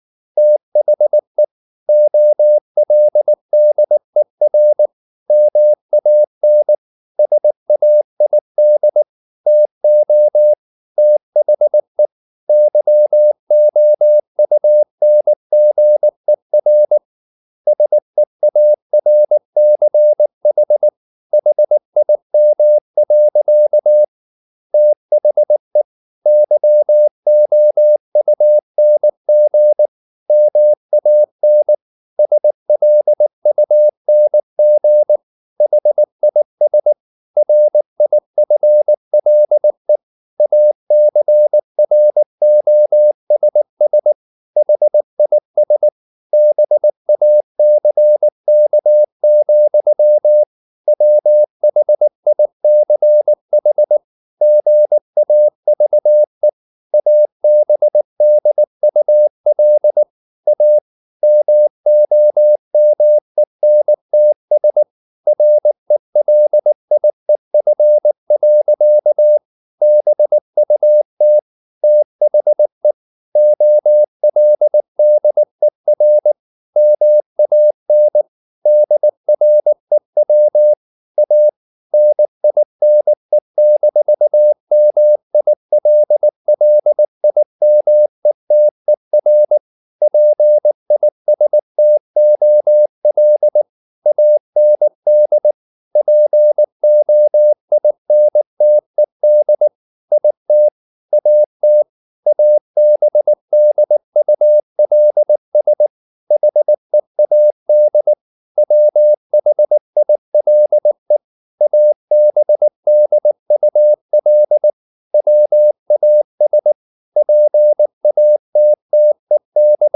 Never 19wpm | CW med Gnister